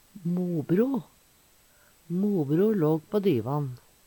DIALEKTORD PÅ NORMERT NORSK mobro mor sin bror - onkel Eintal ubunde Eintal bunde Fleirtal ubunde Fleirtal bunde Eksempel på bruk Mobro låg på divan. Høyr på uttala Ordklasse: Substantiv hokjønn Attende til søk